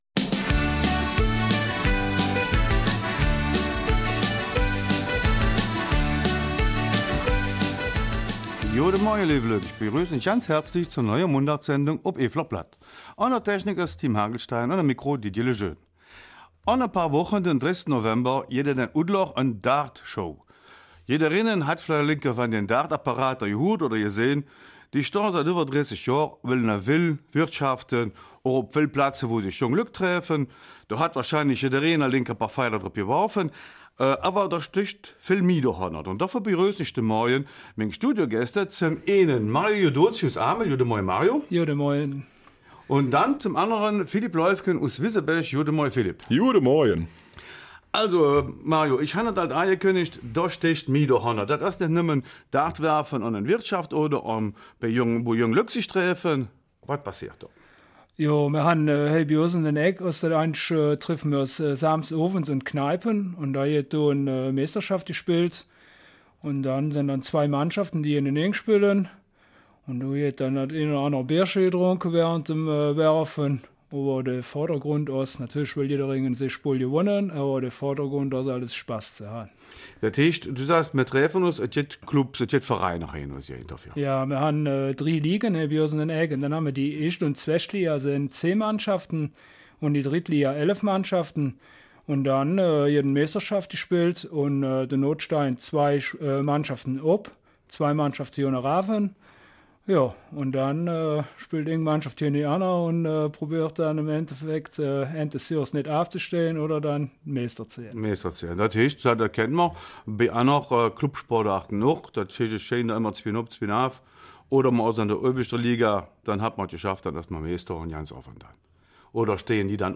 Eifeler Mundart: Darts-Show Ostbelgien